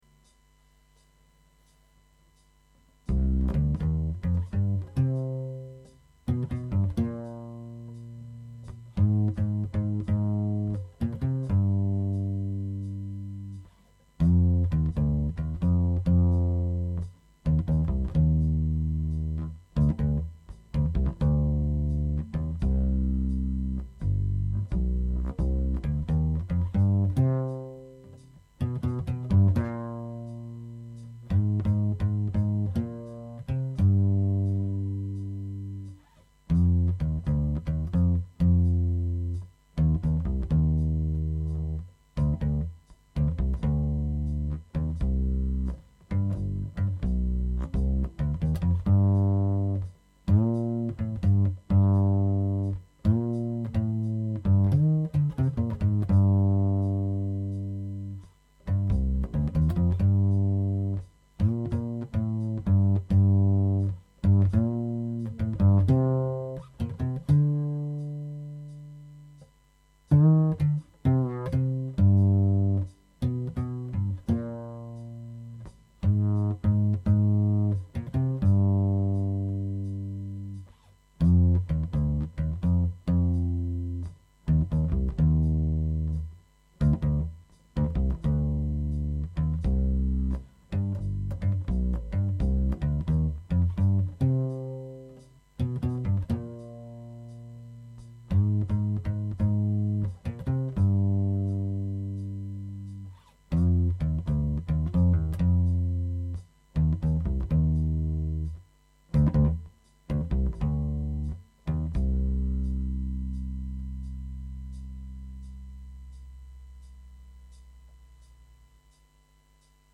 The Arco Acoustic Bass Guitar